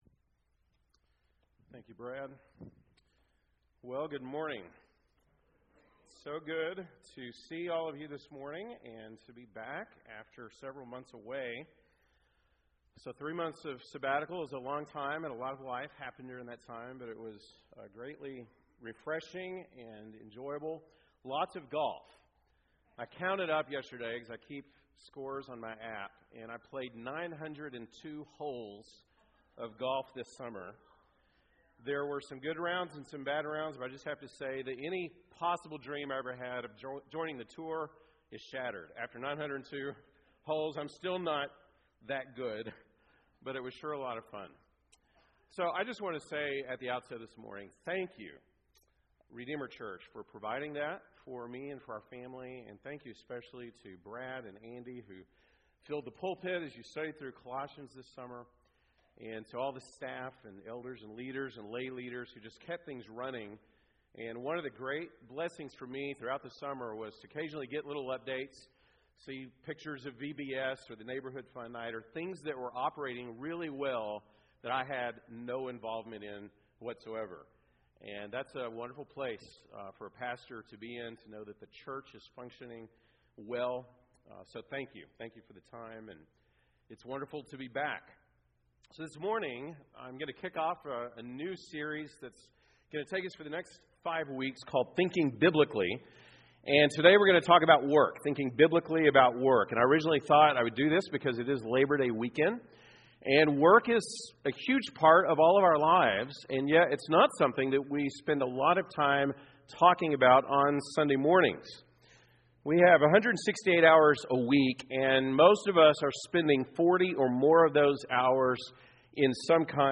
September 3, 2023 (Sunday Morning)